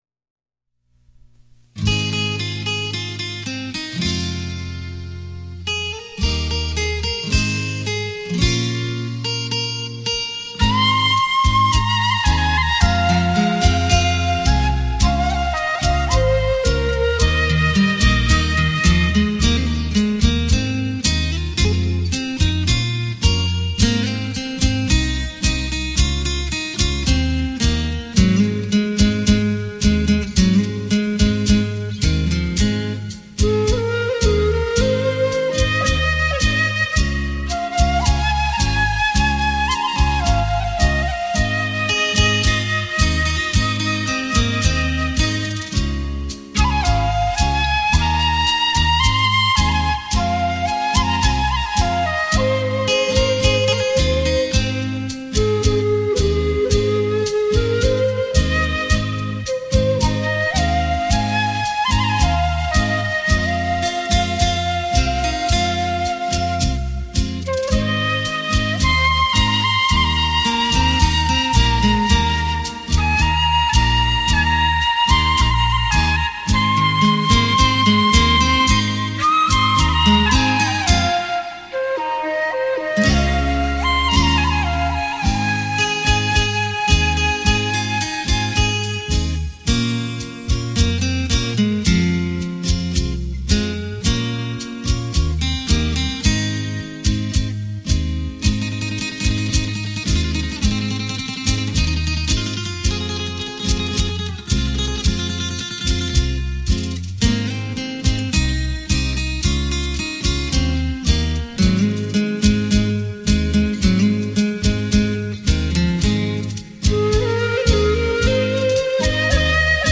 用洞箫悠扬的音色带您回味这曲曲脍炙人口扣人心弦的经典好歌。